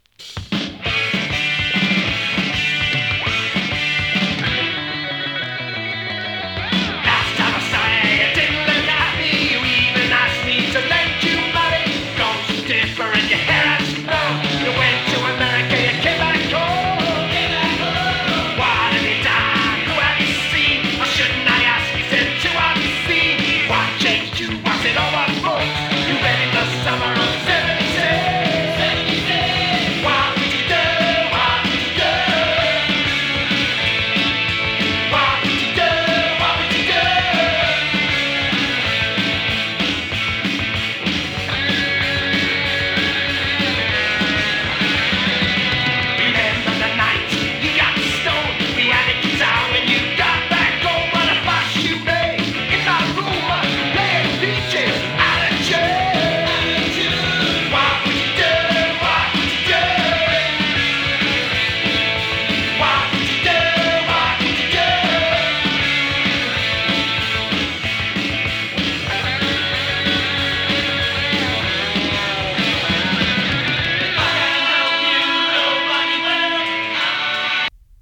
疾走 初期パンク